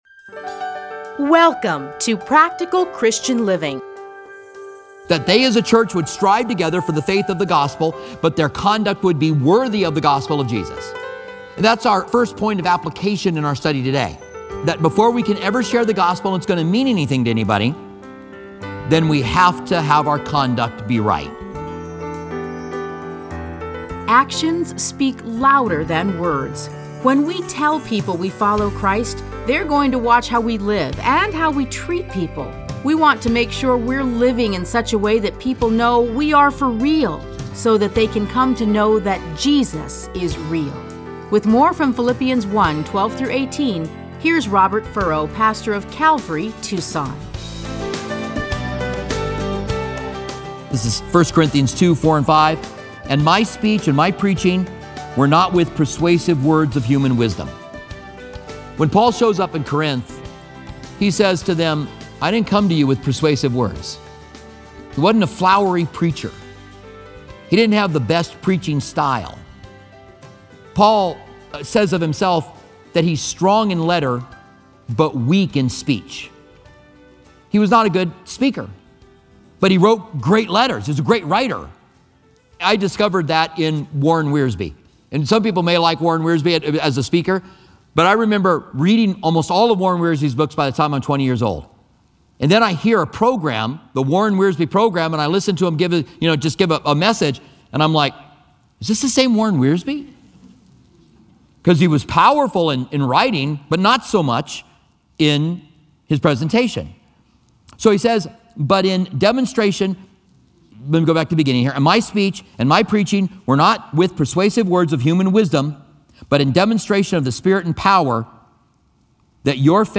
teachings are edited into 30-minute radio programs titled Practical Christian Living. Listen to a teaching from A Study in Philippians 1:12-18.